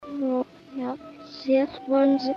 Backwards